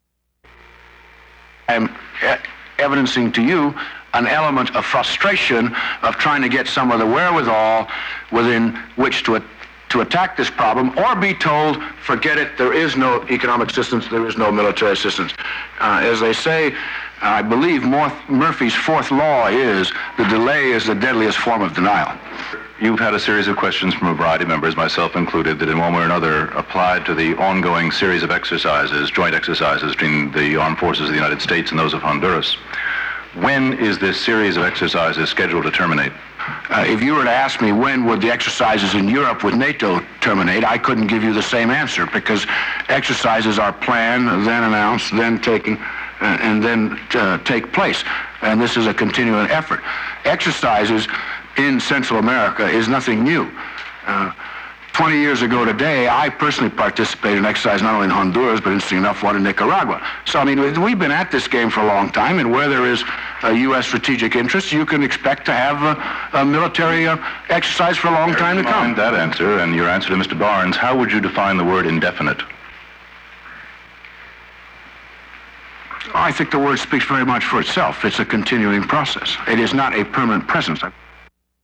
U.S. Undersecretary of State Langhorne Motley testifies before House Foreign Affairs Subcommittee
Committee on Foreign Affairs Subjects Diplomatic relations United States Latin America Material Type Sound recordings Language English Extent 00:01:22 Venue Note Broadcast 1984 May 2.